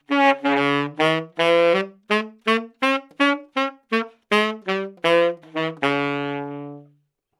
萨克斯次中音音阶 " 萨克斯次中音 D小调
描述：在巴塞罗那Universitat Pompeu Fabra音乐技术集团的goodsounds.org项目的背景下录制。单音乐器声音的Goodsound数据集。
标签： 好声音 男高音 萨克斯 纽曼-U87 Dminor 规模
声道立体声